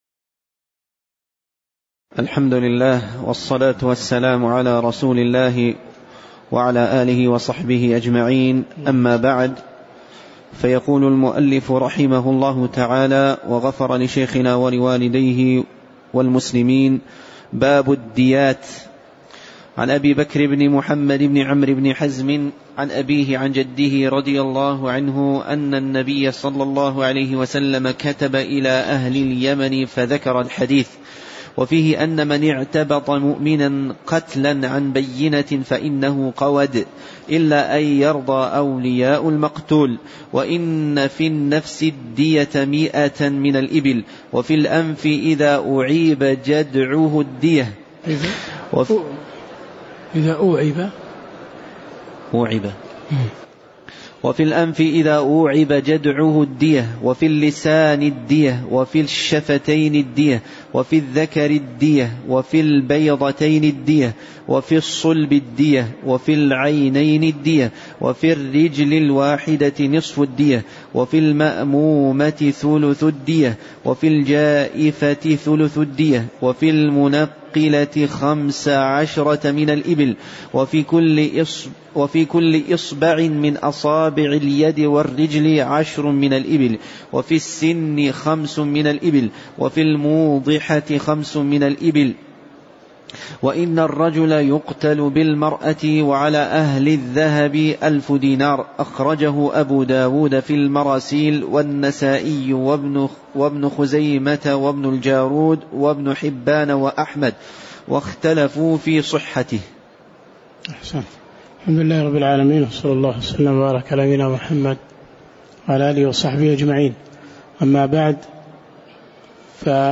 تاريخ النشر ١٨ جمادى الآخرة ١٤٣٩ هـ المكان: المسجد النبوي الشيخ